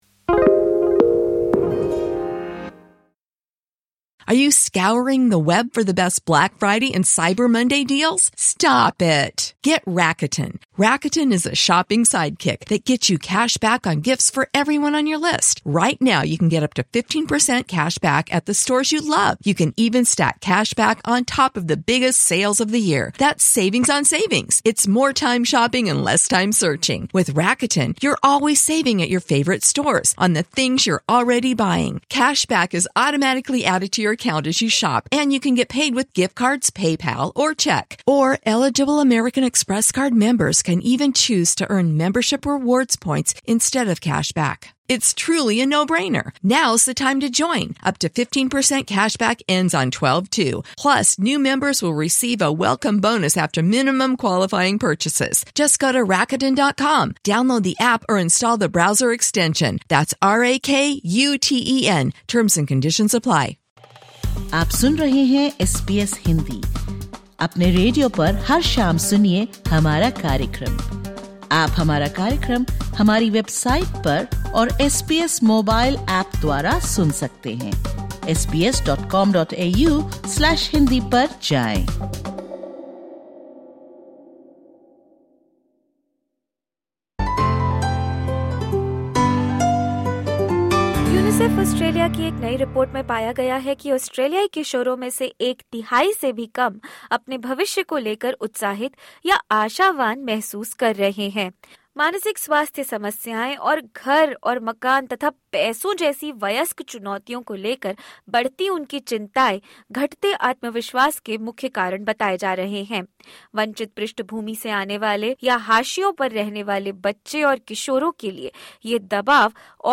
(Disclaimer: The information given in this interview is of a general nature.